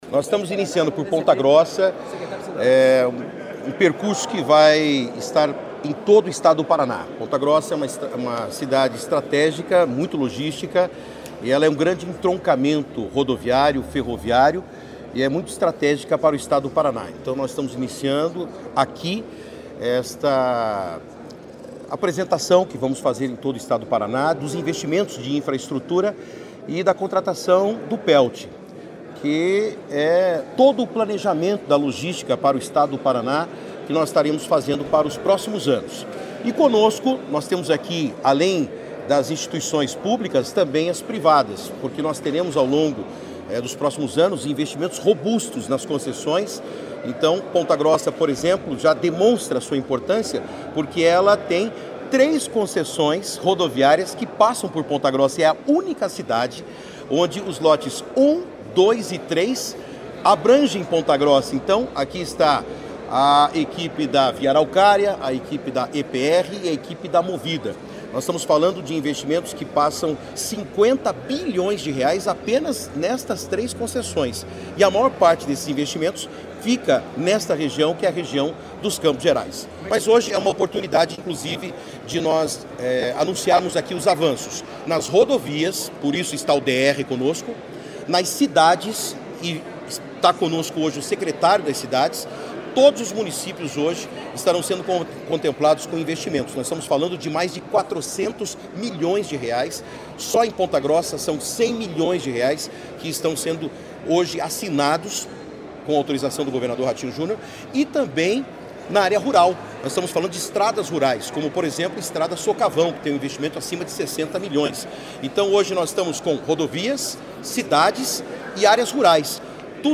Sonora do secretário de Infraestrutura e Logística, Sandro Alex, sobre o Plano Estadual de Logística e Transportes